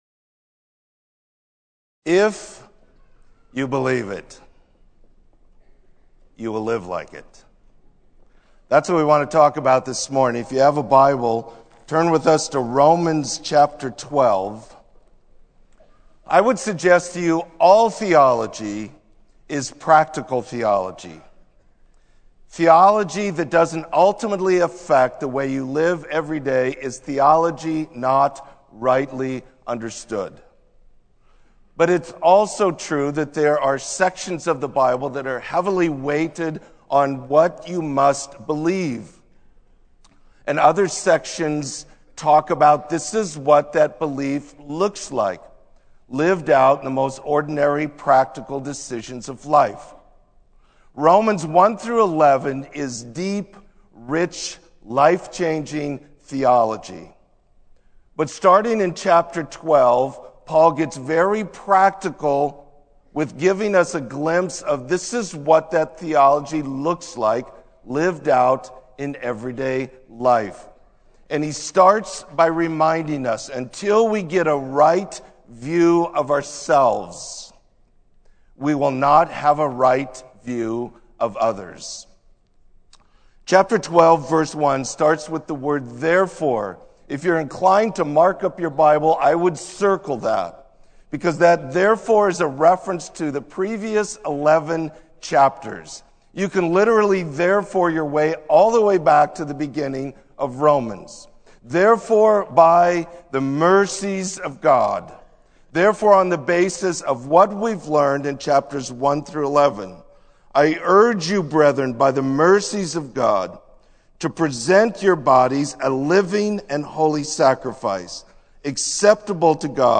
Sermon: A Renewed Mind